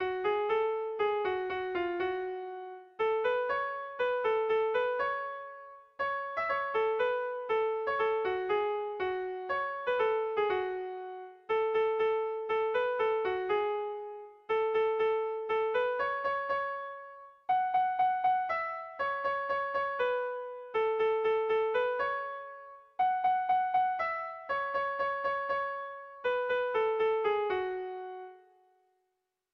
ABDE....